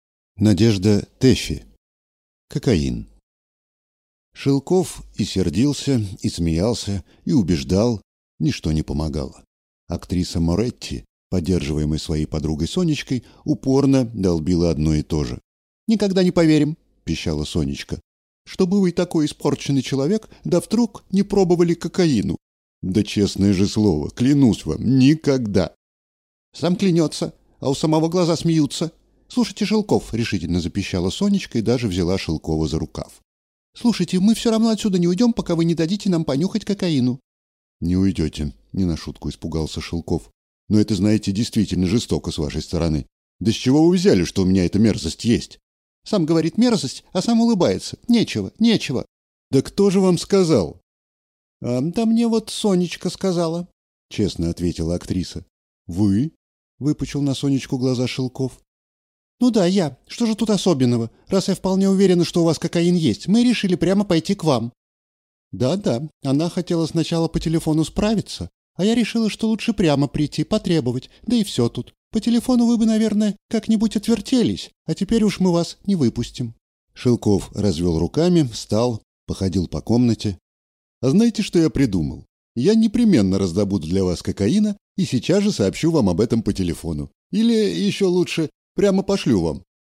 Аудиокнига Кокаин | Библиотека аудиокниг